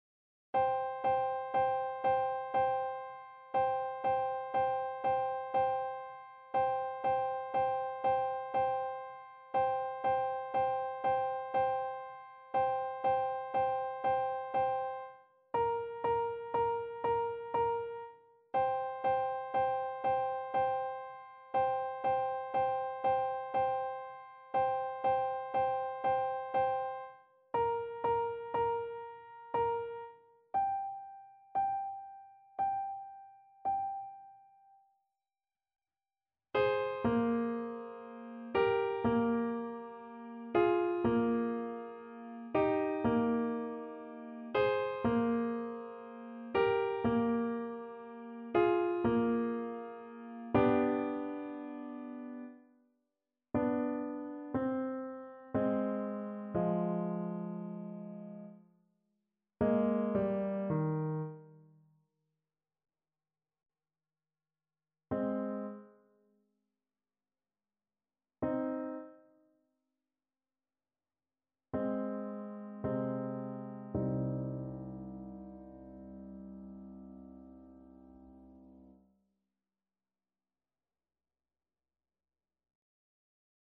3/4 (View more 3/4 Music)
Andante sostenuto =60
Classical (View more Classical French Horn Music)